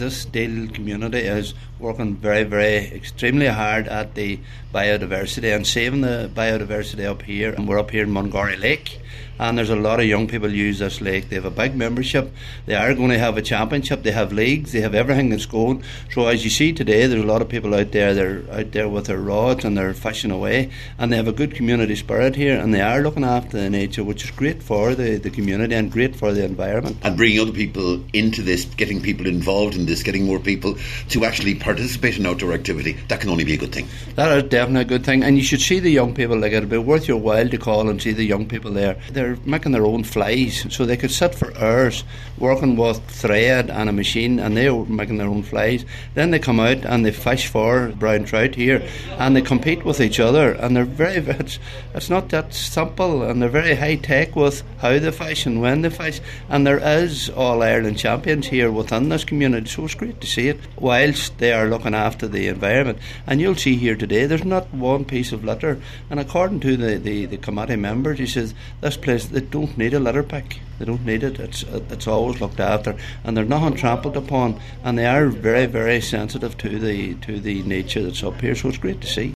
Cathaoirleach Cllr Paul Canning says the work done by Deele Community Anglers to get young people involved in their activities is a perfect example of what this programme is intended to achieve……..